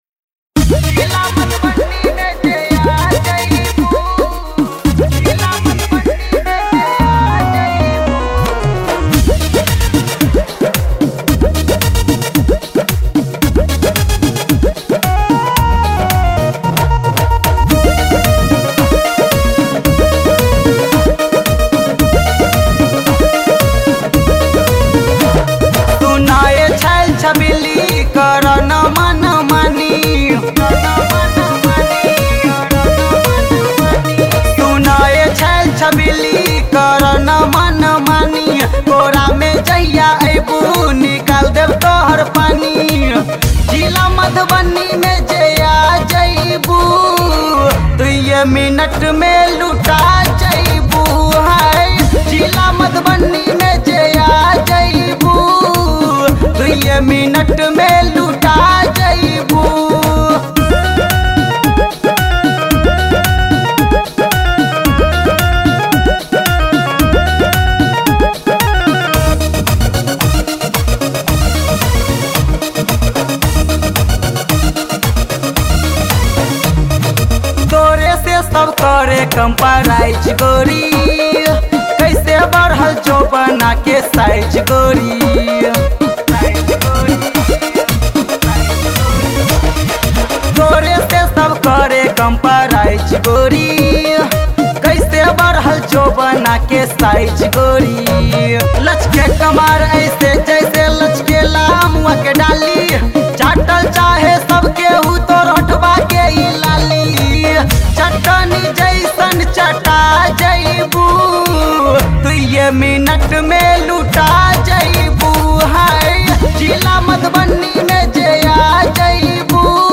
Maithili Songs